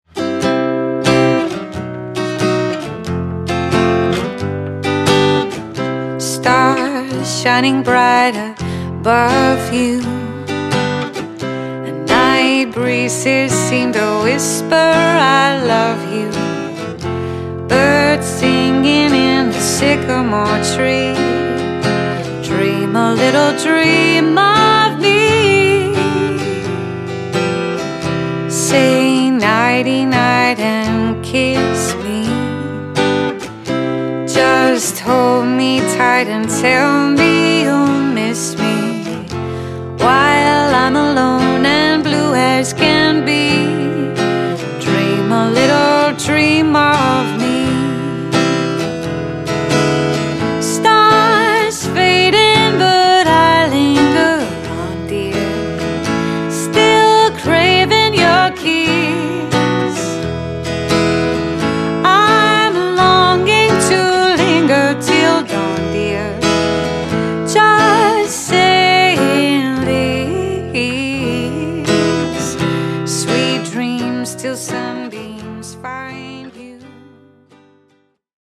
acoustic music